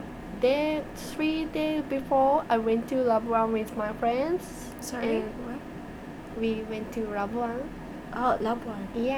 S1 = Brunei female S2 = Japanese female Context: They have been talking about watching movies.
Discussion: The initial consonant may be [r] rather than the expected [l].